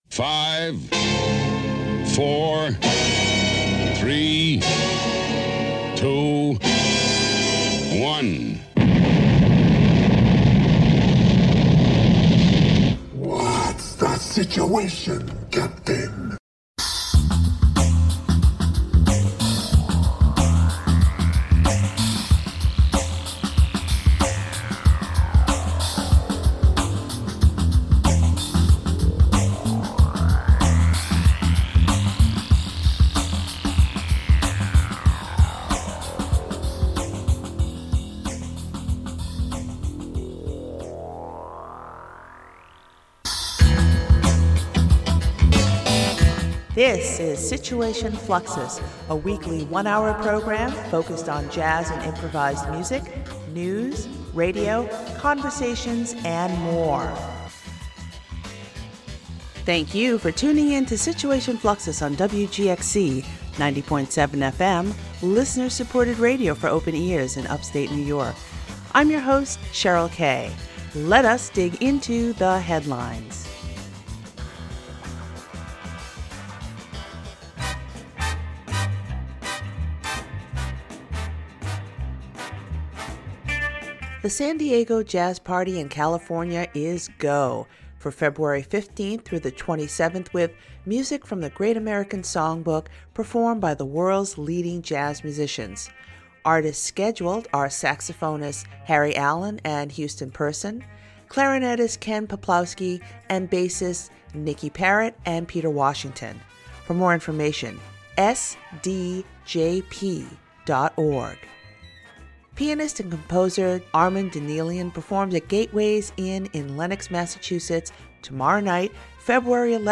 In this broadcast, a conversation with tenor saxophonist Javon Jackson and renowned African American poet, activist, and educator Nikki Giovanni.
A weekly one-hour program focused on jazz and improvised music, news, radio, conversations, and more.